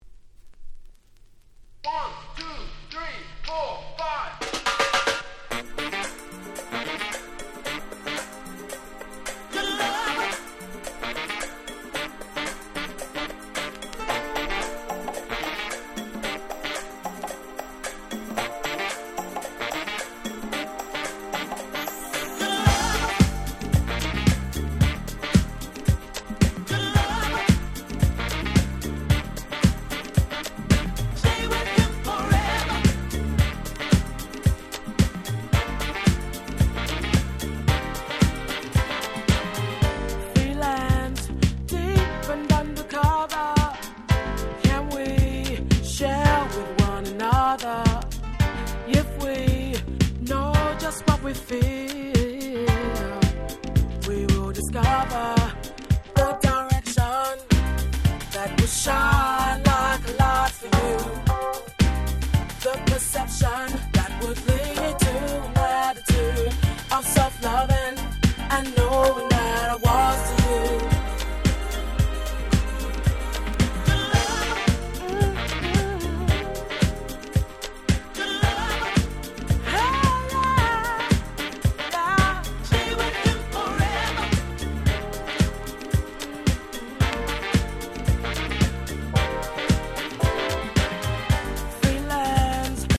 Nice UK R&B EP !!
Acid Jazz アシッドジャズ